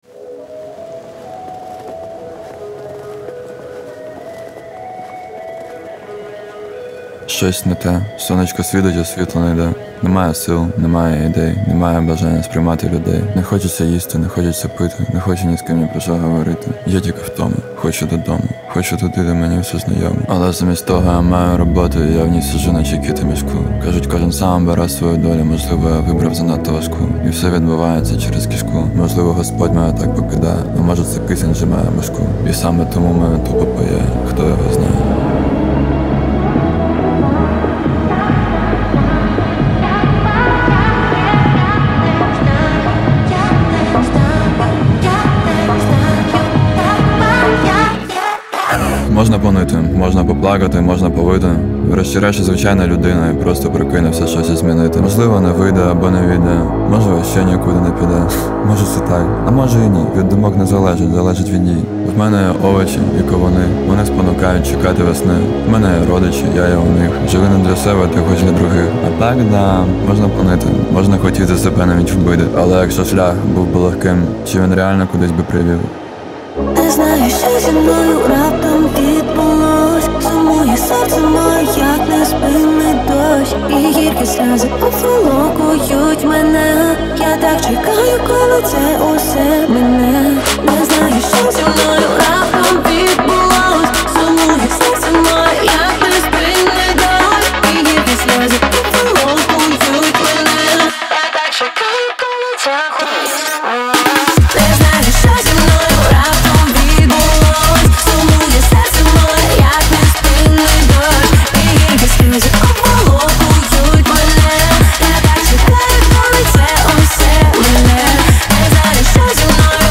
• Жанр: Pop, Hip-Hop